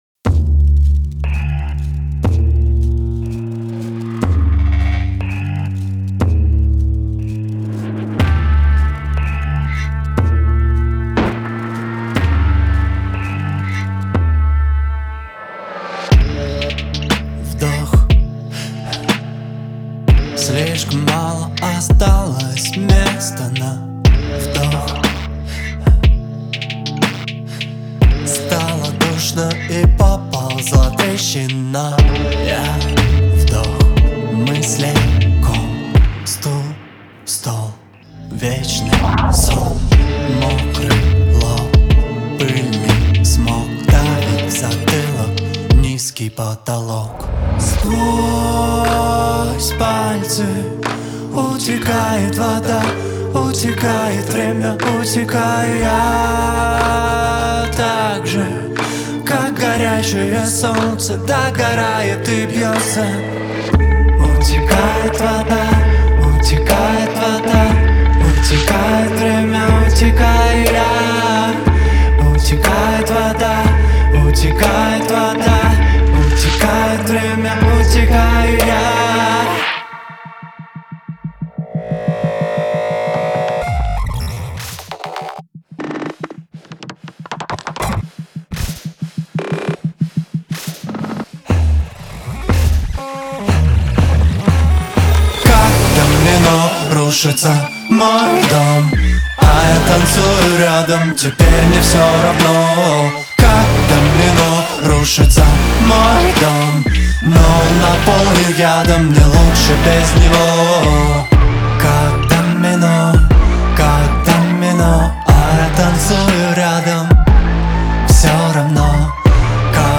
это завораживающая поп-песня с элементами R&B